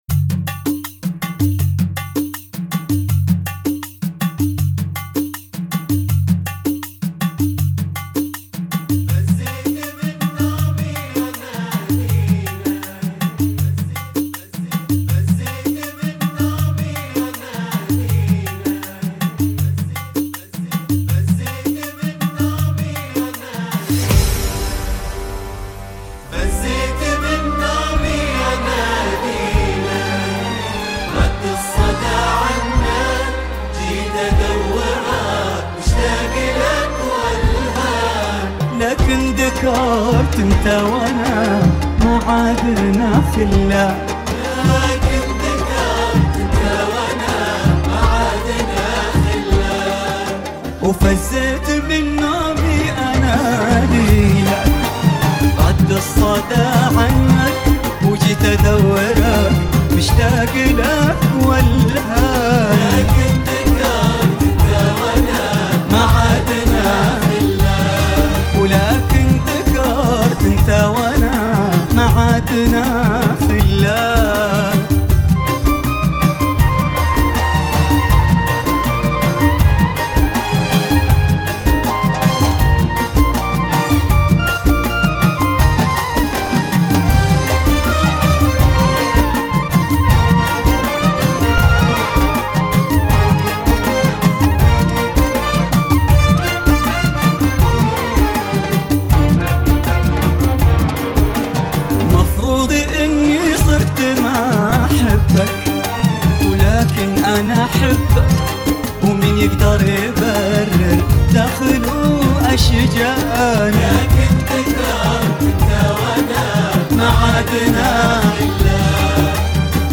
80 bpm